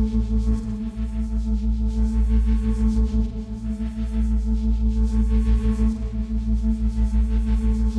Index of /musicradar/dystopian-drone-samples/Tempo Loops/90bpm
DD_TempoDroneB_90-A.wav